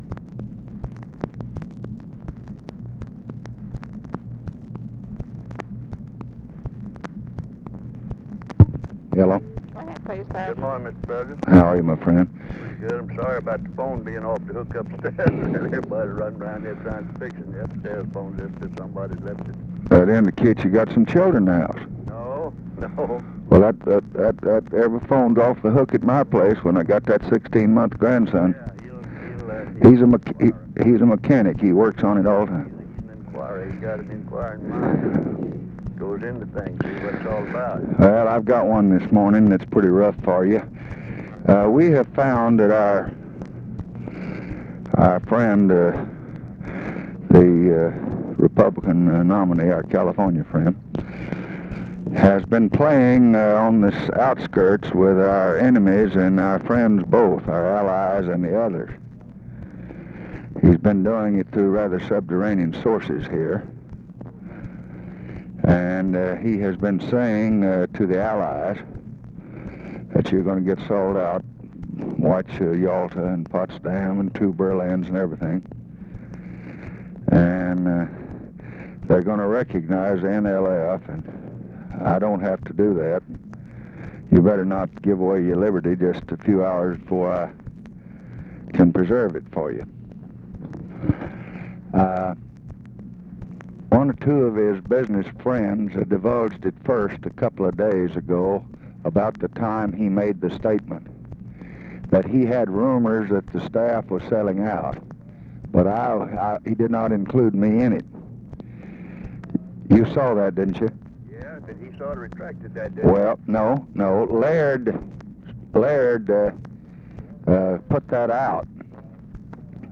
Conversation with RICHARD RUSSELL, October 30, 1968
Secret White House Tapes